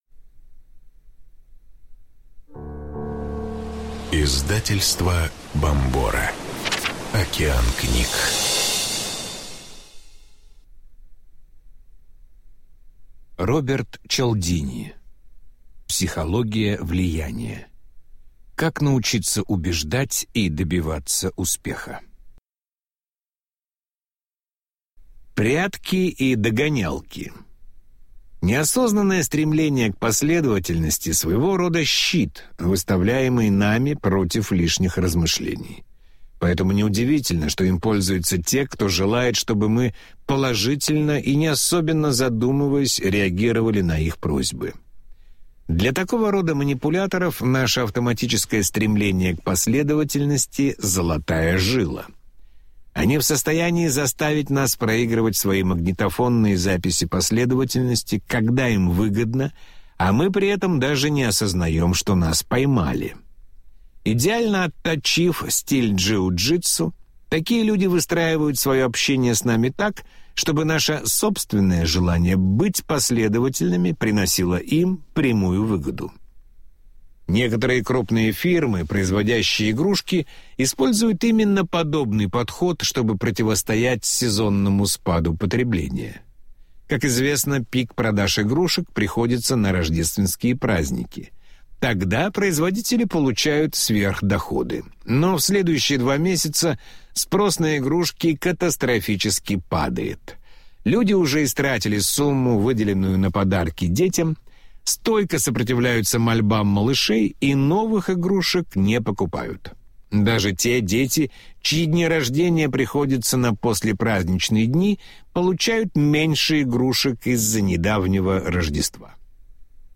Аудиокнига Психология влияния. 7-е расширенное издание | Библиотека аудиокниг